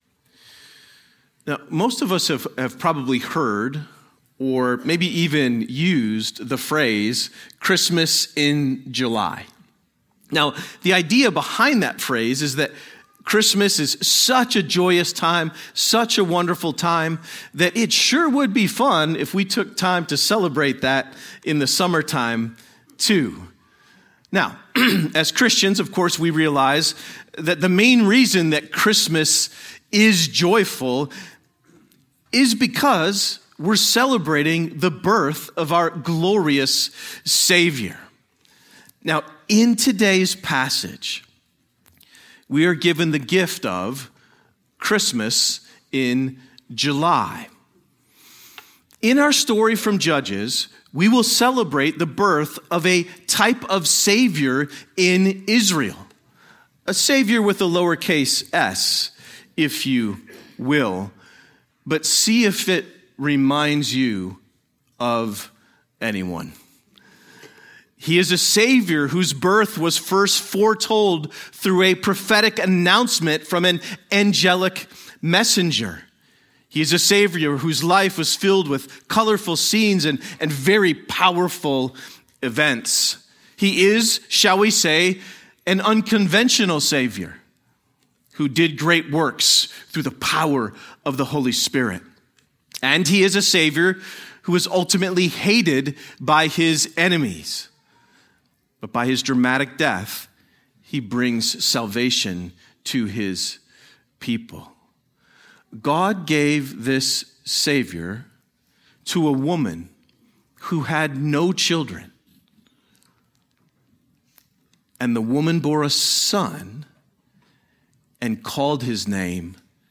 A sermon on Judges 13